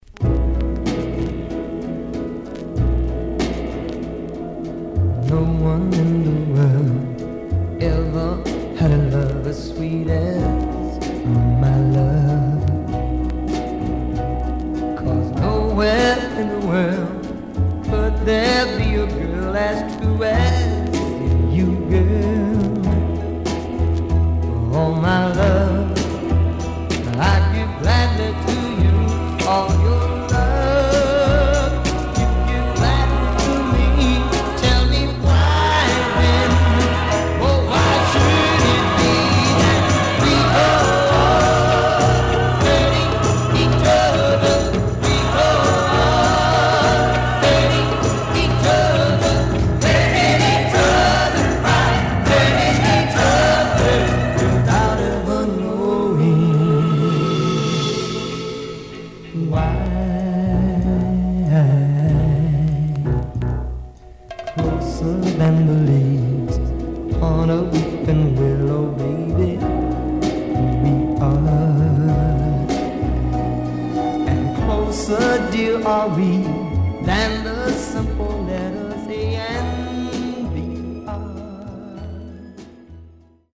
Surface noise/wear
Mono
Soul